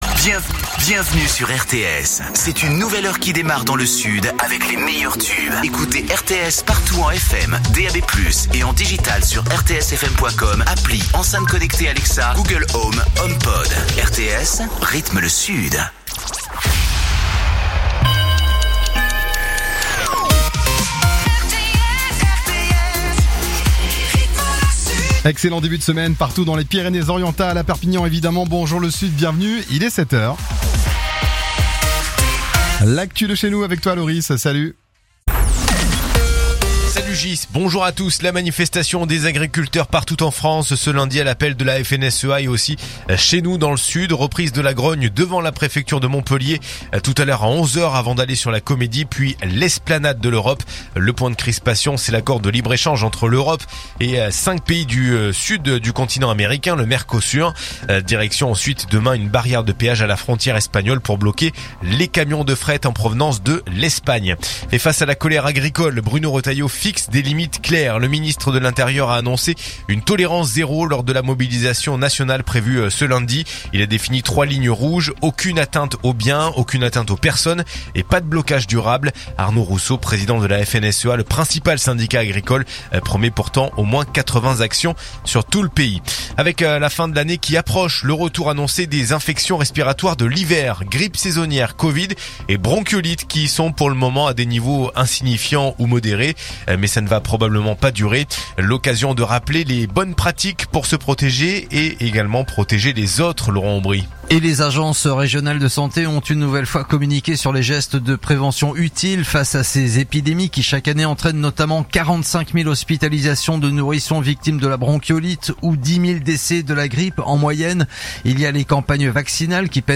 Écoutez les dernières actus de Perpignan en 3 min : faits divers, économie, politique, sport, météo. 7h,7h30,8h,8h30,9h,17h,18h,19h.
info_perpignan_206.mp3